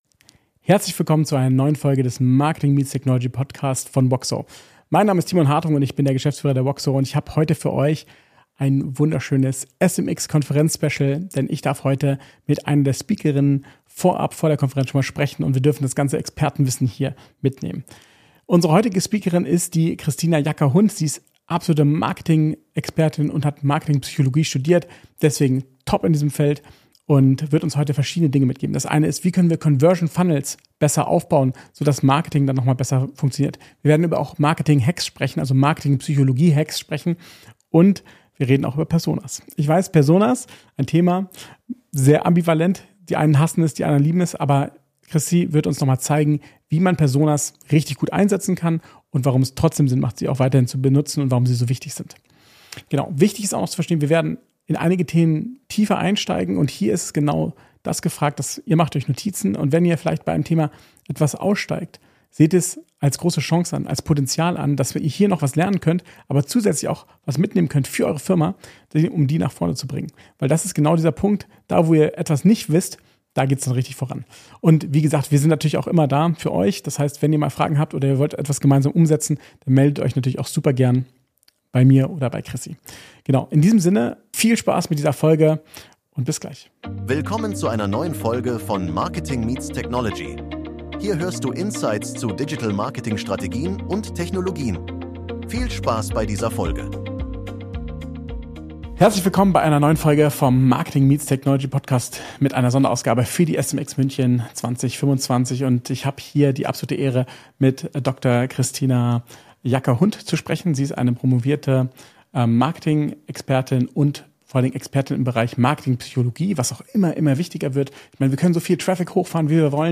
SMX Special: Neuer SEO Trend? Die radikale Veränderung der Online-Suche | Interview